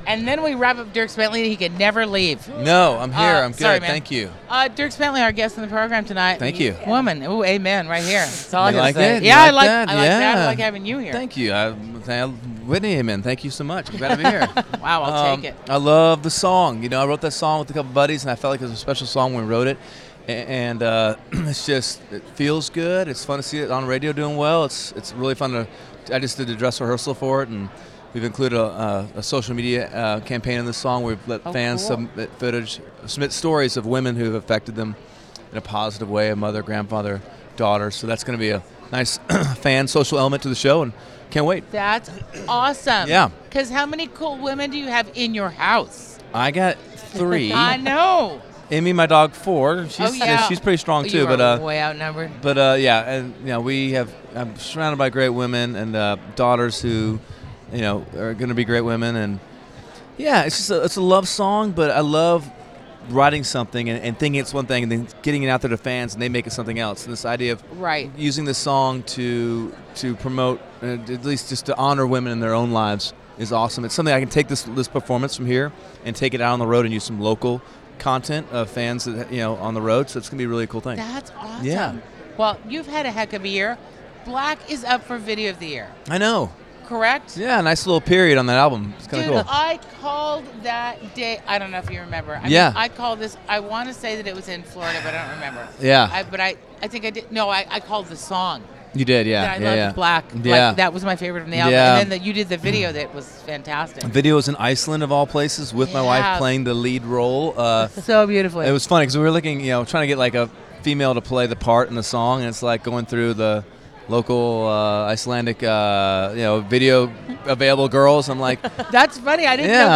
Dierks Bentley Interview At 2018 ACMs!